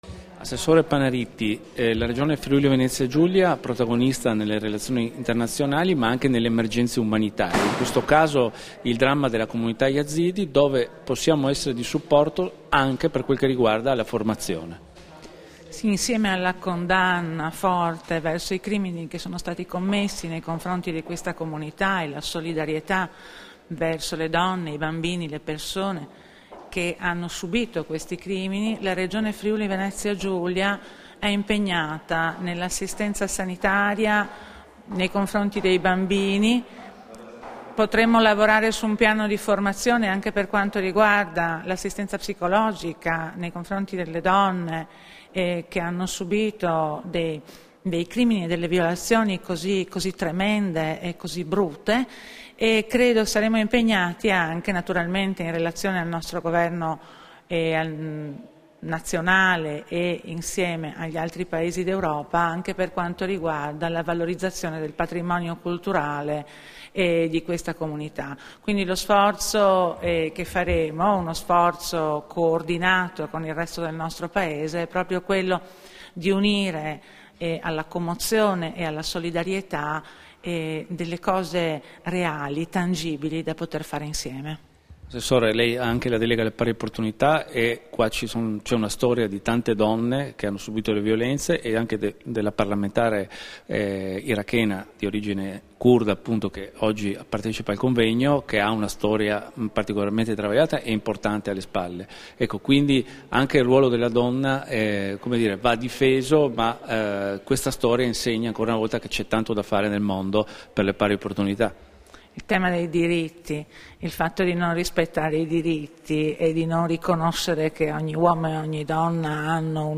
Dichiarazioni di Loredana Panariti (Formato MP3)
a margine del convegno sui progetti di Cooperazione internazionale in corso a favore della Comunità Yazidi, rilasciate a Gorizia il 27 maggio 2016